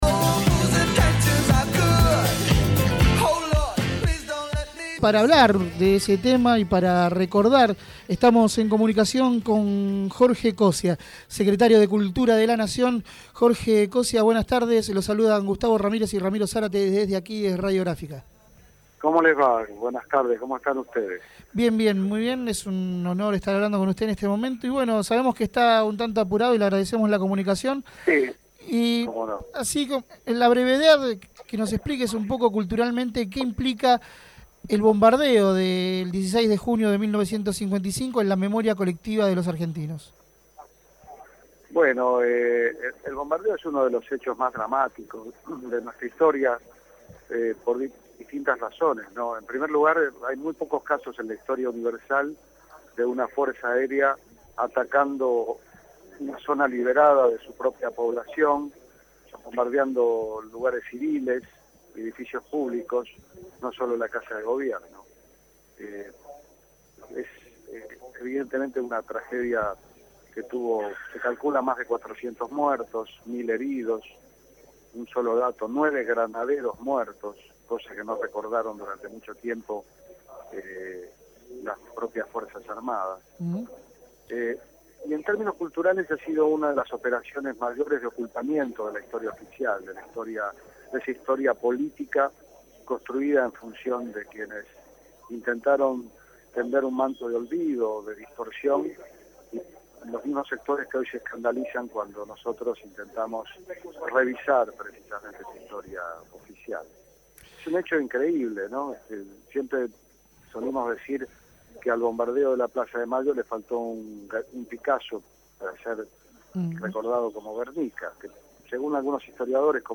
Jorge Coscia, Secretario de Cultura de la Nación, habló con Feos, Sucios y Malas.